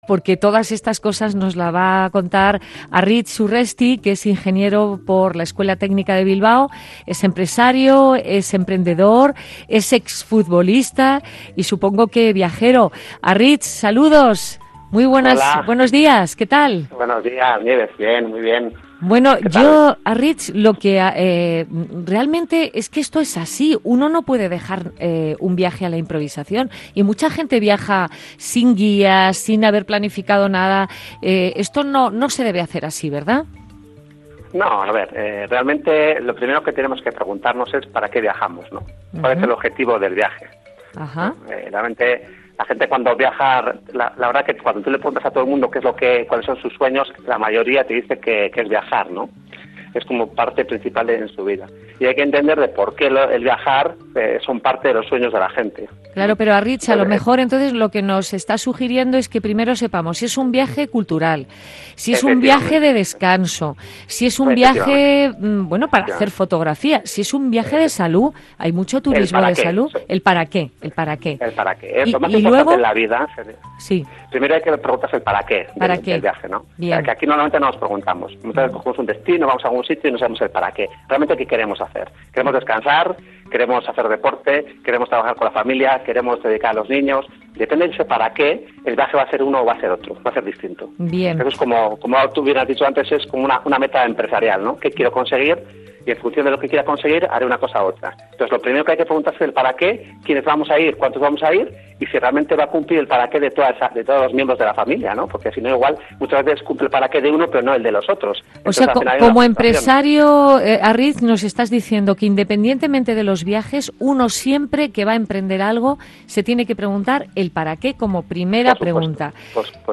Nieves Herrero entrevista